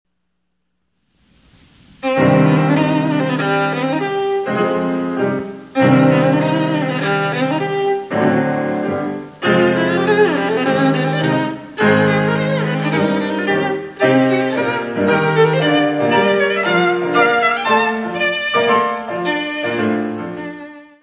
: mono; 12 cm
Music Category/Genre:  Classical Music
Allegro molto ed appassionato.